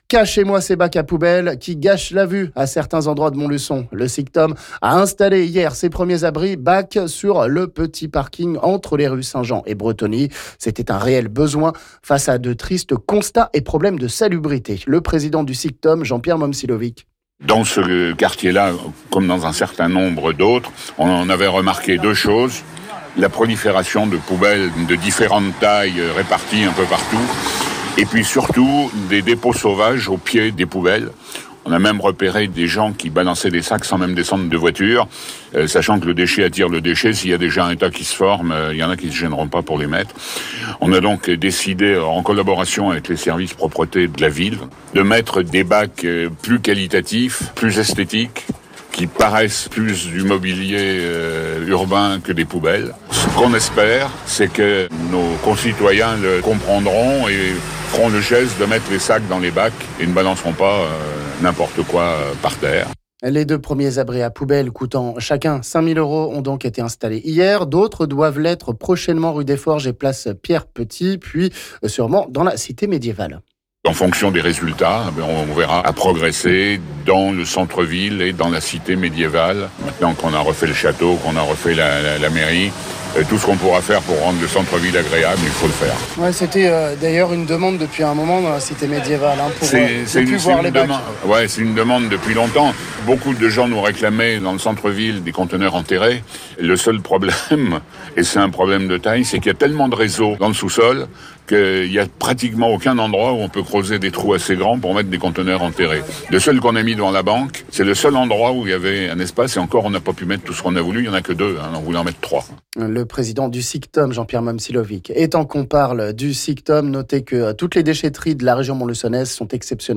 On écoute le président du SICTOM Jean-Pierre Momcilovic...